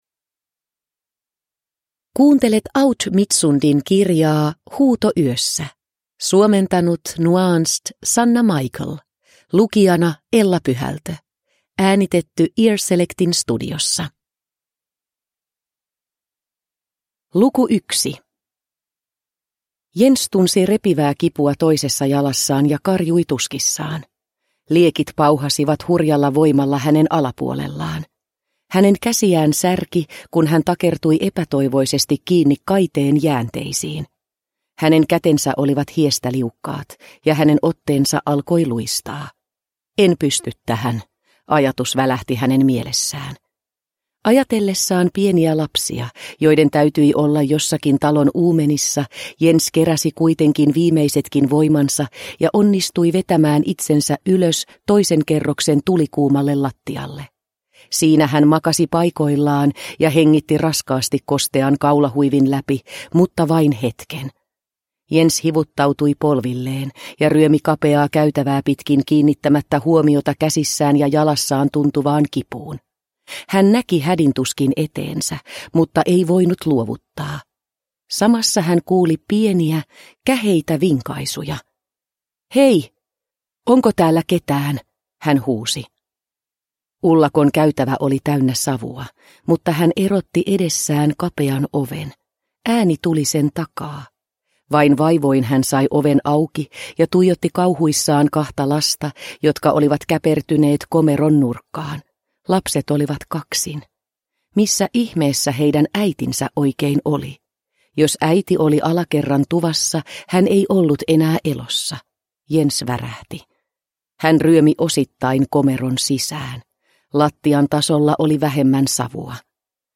Huuto yössä (ljudbok) av Aud Midtsund